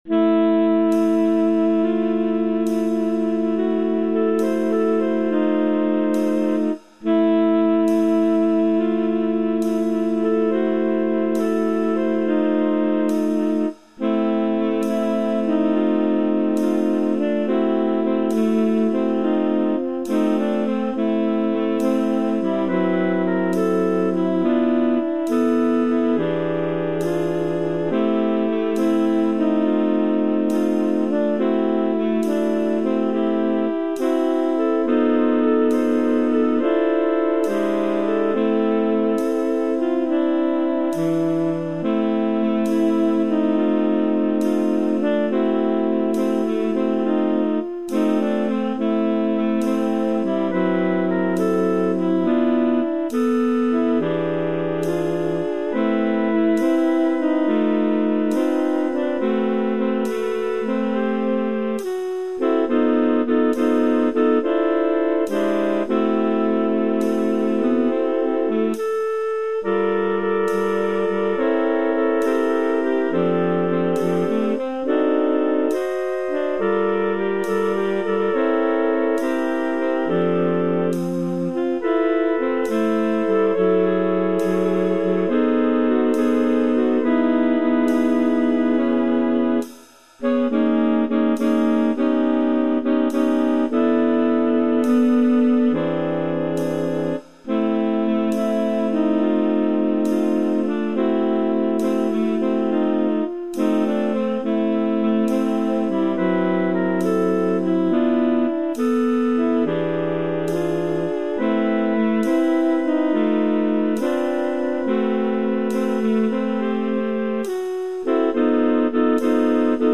Jazz standard
SSAA
Een langzame, smartelijke jazz ballad